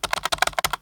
keyboard1.ogg